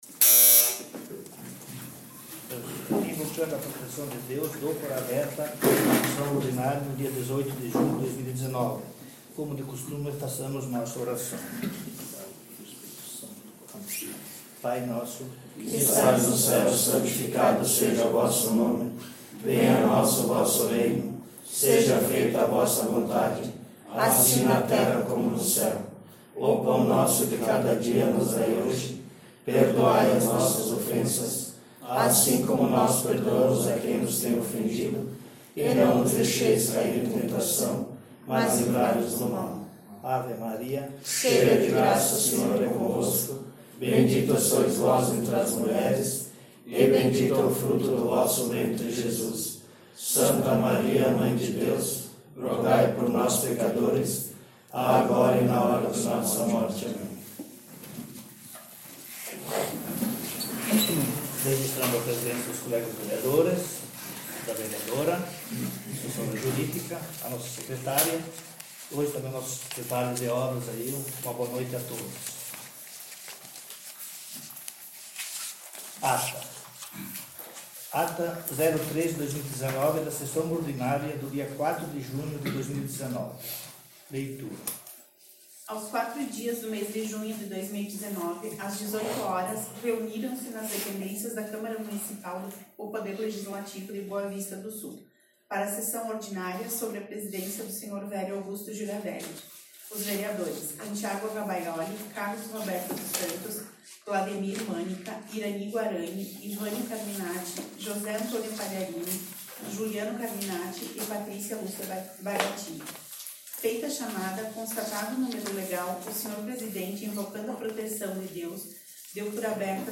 Sessão Ordinária dia 18/06/19